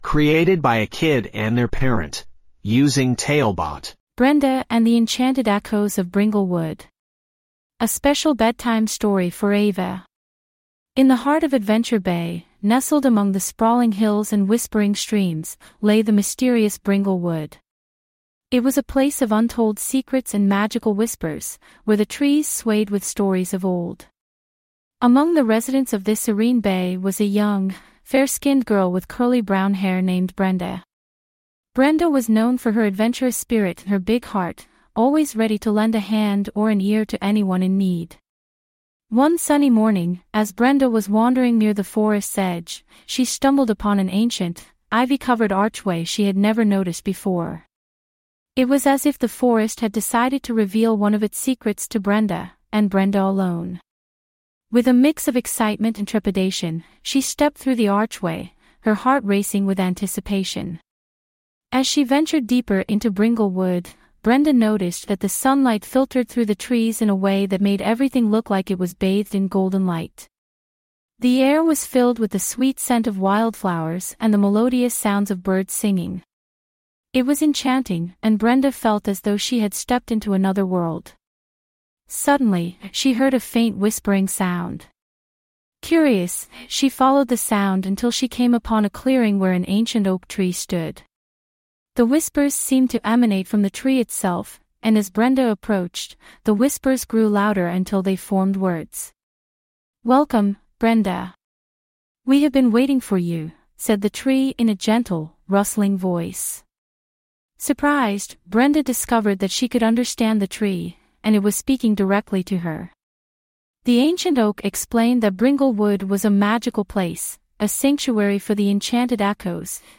5 Minute Bedtime Stories